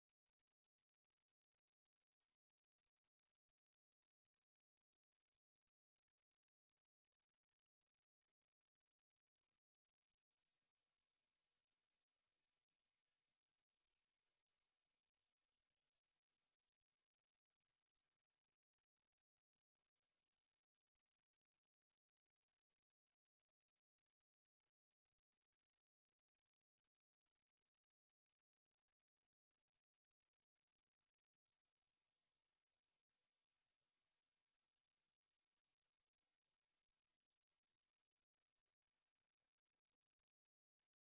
Boat Lift Servo
Boat Lift; Servo raise and lower; Metal stress; Oceanus, Woods Hole, MA
32kbps-Boat-Lift-Servo-raise-and-lower-Metal-stress.mp3